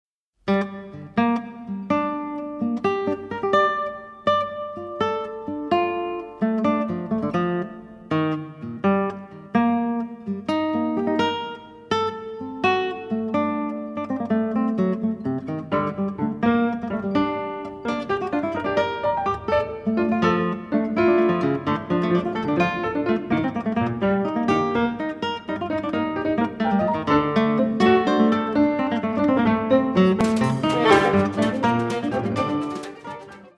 Geige
Klavier
Schlagwerker